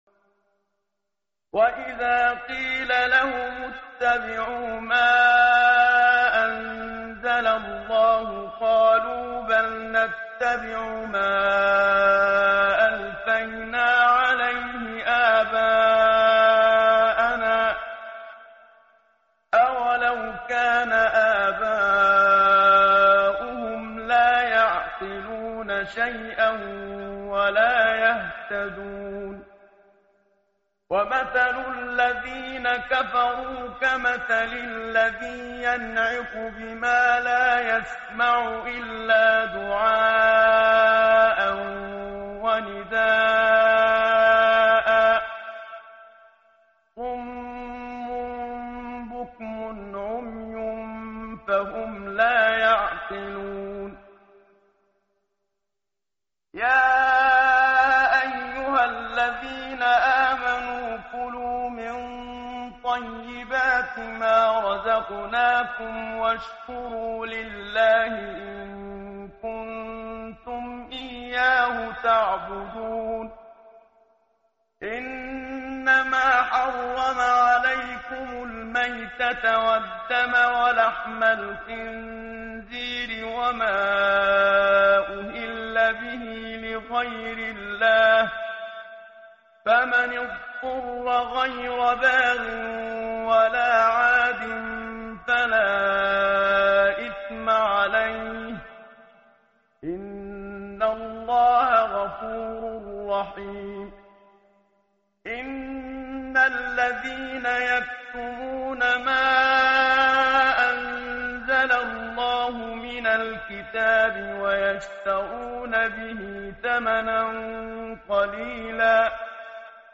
متن قرآن همراه باتلاوت قرآن و ترجمه
tartil_menshavi_page_026.mp3